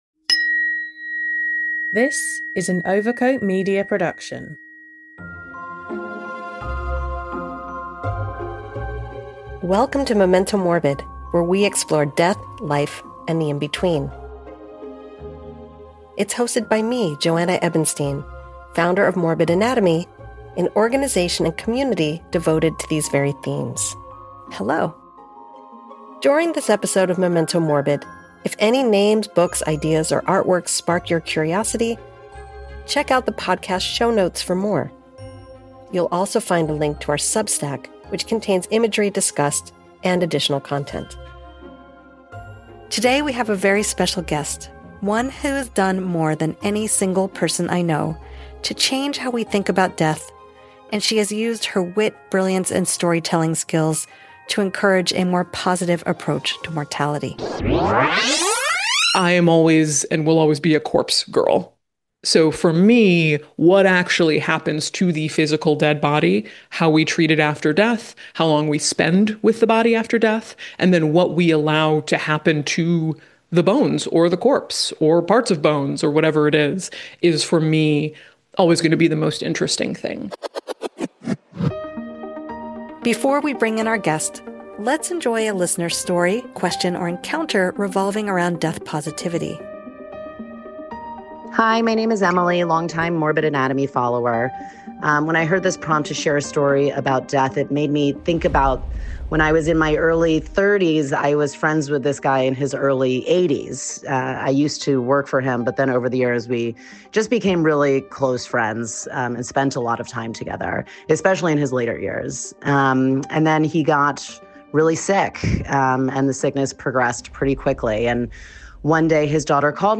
a candid, curious and surprisingly funny conversation about what really happens to our bodies after we die and why modern Western culture would rather not think about it.